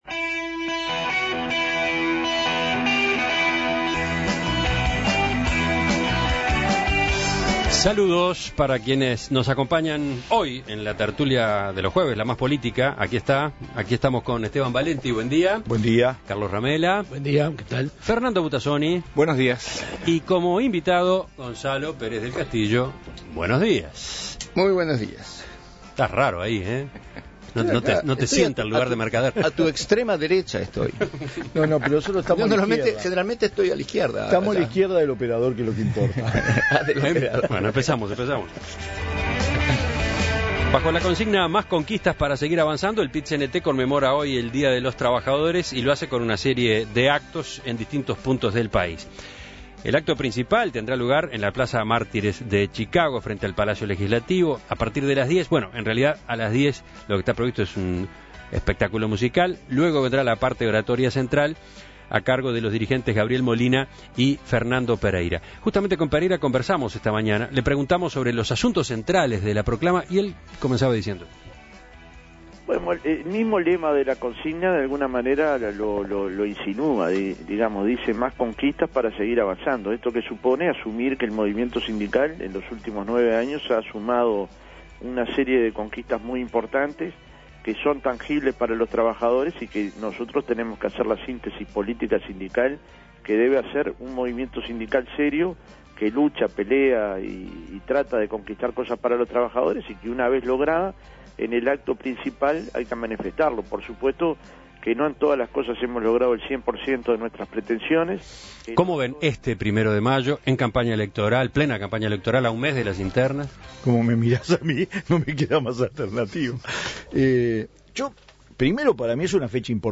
El encuentro principal tuvo lugar en la Plaza Mártires de Chicago, frente al Palacio Legislativo, y comenzó a las 10 de la mañana con un show musical.